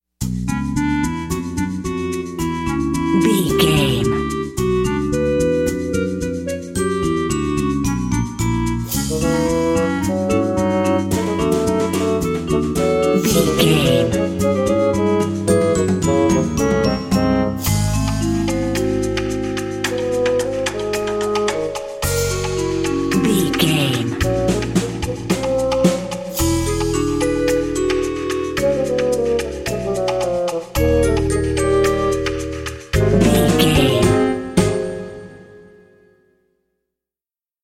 Aeolian/Minor
C#
percussion
flute
bass guitar
silly
circus
goofy
comical
cheerful
perky
Light hearted
quirky